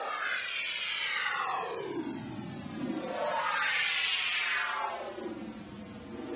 对比 " 遥远的信号无人机
描述：遥远的，混响的无人机，就像太空中丢失的信号一样。 C键，80bpm。
标签： 脉冲 雄蜂 信号
声道立体声